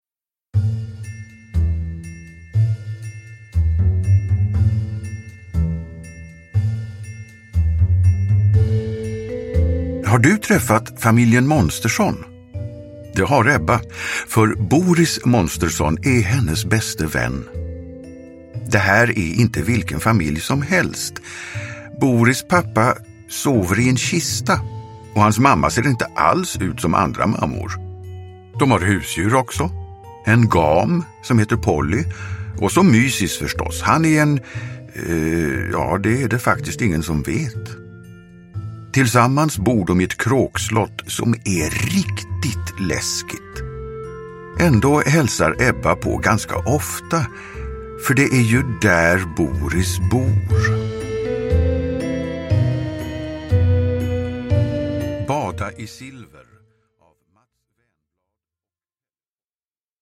Bada i silver – Ljudbok – Laddas ner